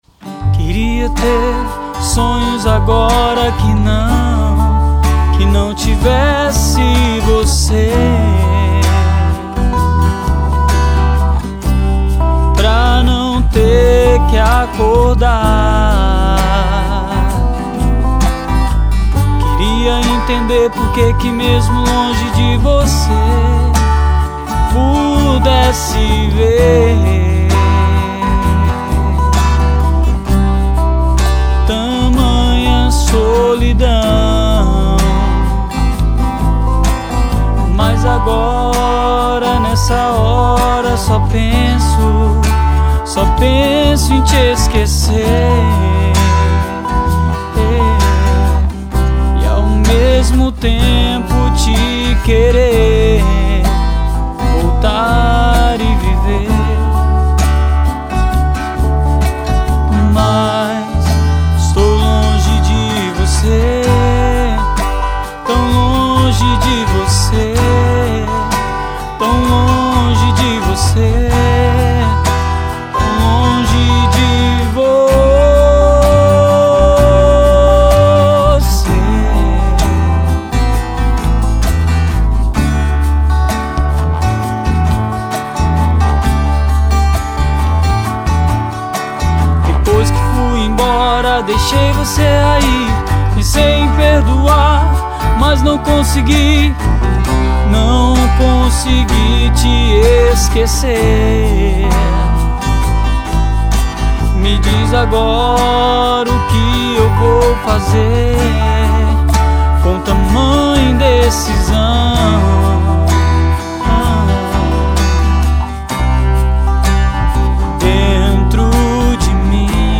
VOLTADA AO GÊNERO MUSICAL DE MPB E POP-ROCK.